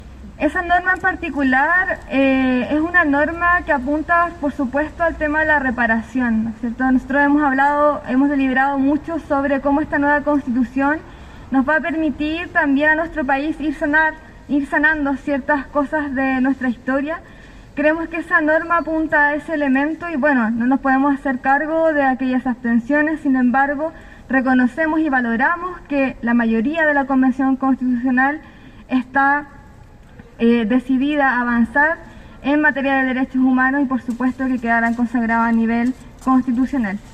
Janis Meneses, coordinadora de la Comisión de Derechos fundamentales se refirió ante este artículo que contó con un amplio patrocinio